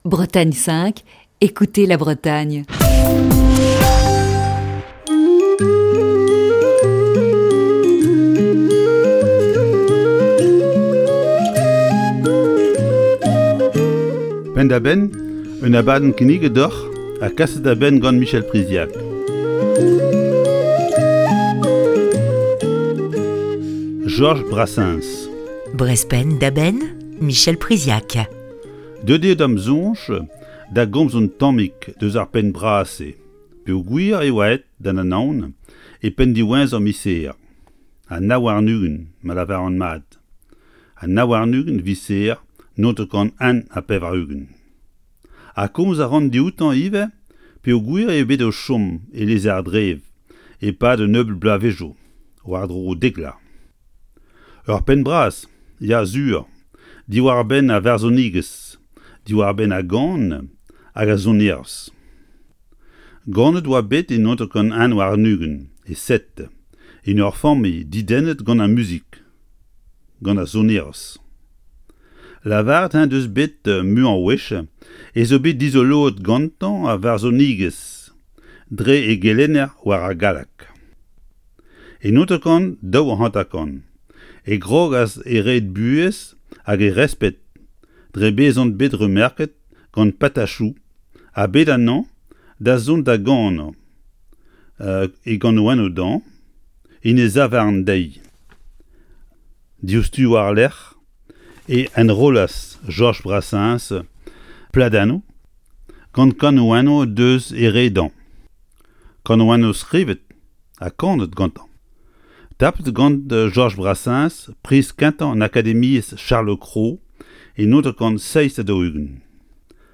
Chronique du 6 novembre 2020.